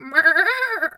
Animal_Impersonations
sheep_2_baa_12.wav